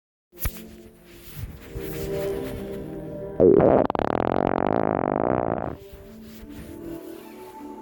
Short Fart_01